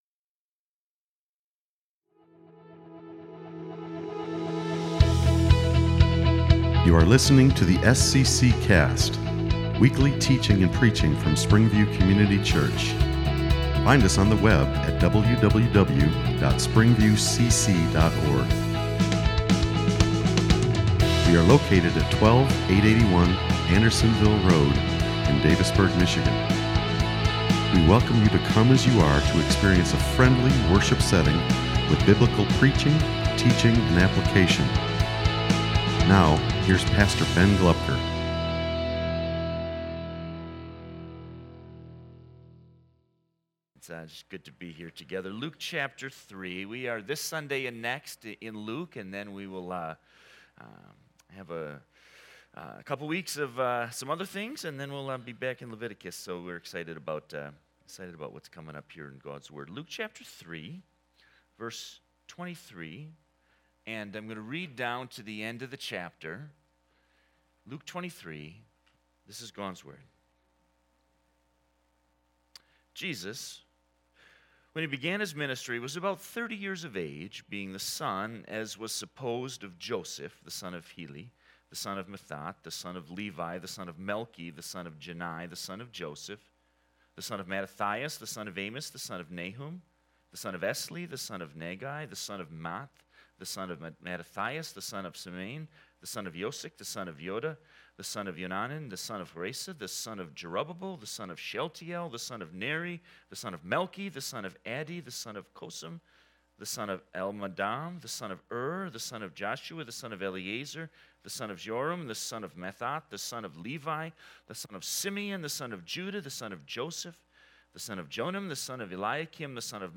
Sermons | SPRINGVIEW COMMUNITY CHURCH